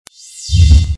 传送.mp3